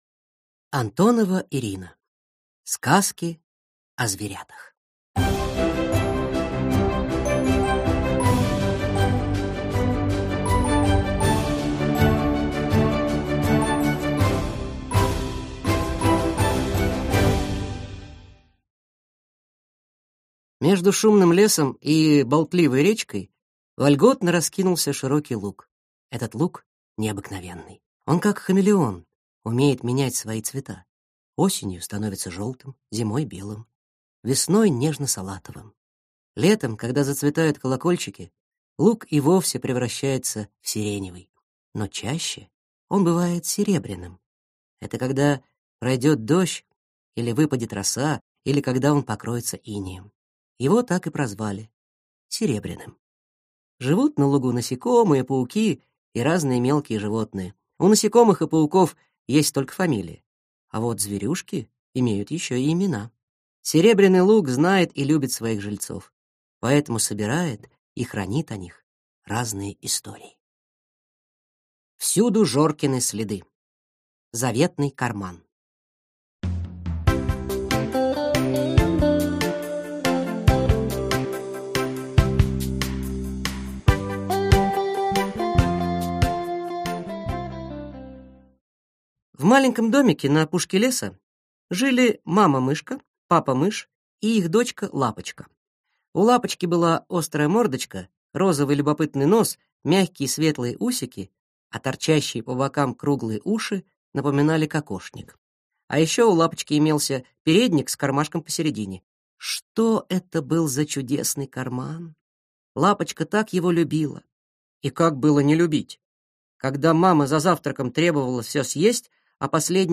Аудиокнига Сказки о зверятах | Библиотека аудиокниг